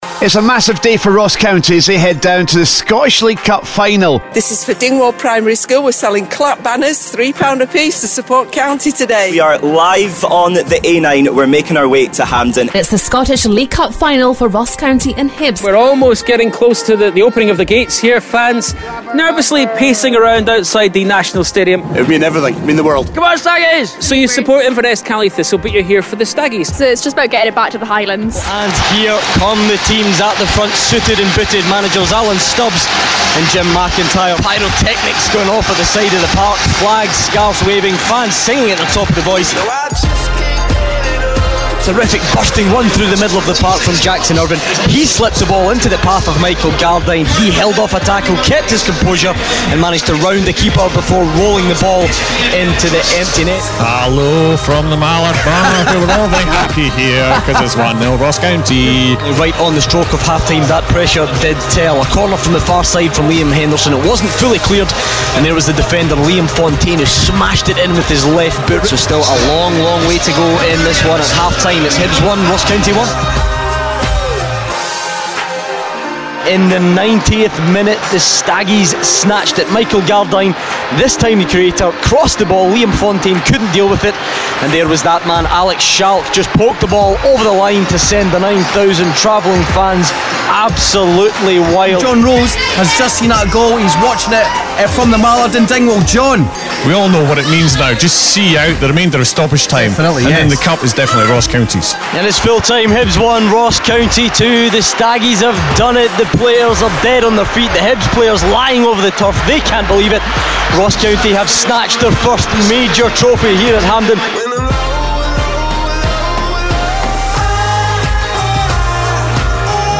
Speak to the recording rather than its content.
Highlights of MFR's coverage of Ross County's win over Hibernian in the final of the Scottish League Cup